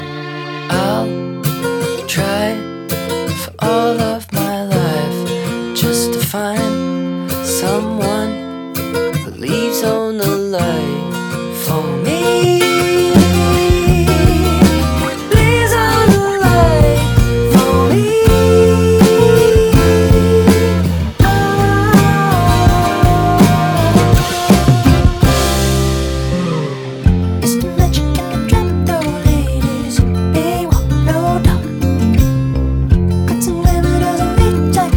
Нестандартные аккорды и неожиданные переходы
2025-04-04 Жанр: Альтернатива Длительность